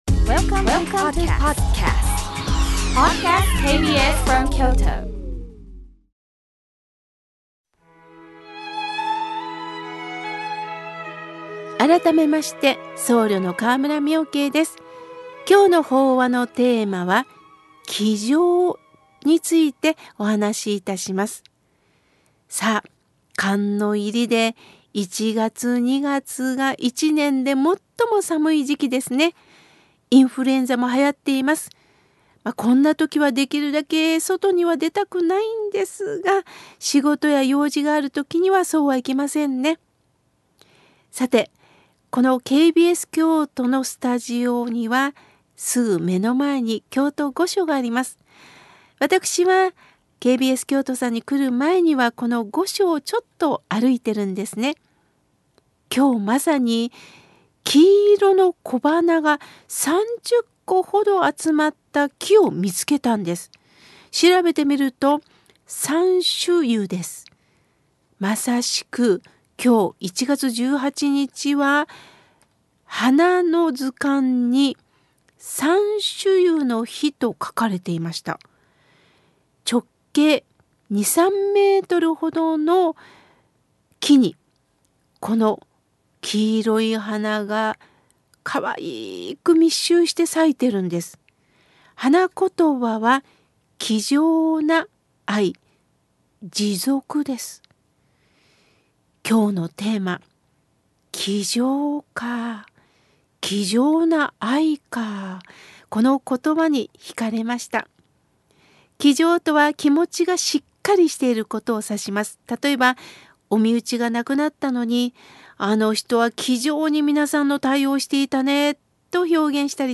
さまざまなお話・エピソードを通して、また、時にはゲストも交えて贈るラジオ番組です。KBS京都ラジオ 毎週土曜日 8:00-8:30 オンエア